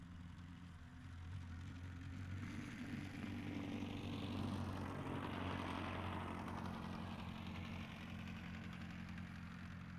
Zero Emission Snowmobile Description Form (PDF)
Zero Emission Subjective Noise Event Audio File (WAV)